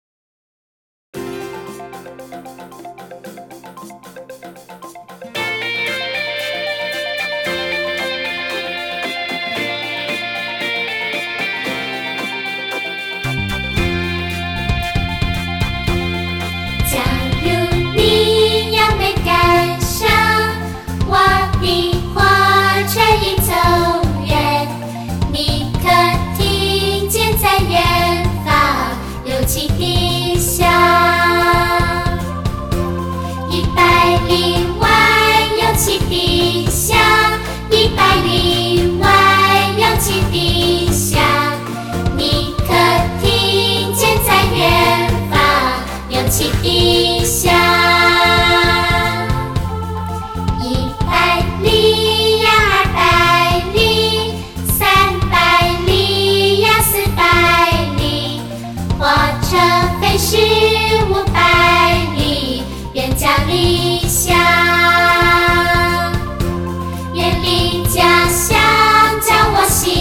老歌新唱，感受环绕音效的包围，